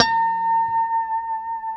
B5 PICKHRM1C.wav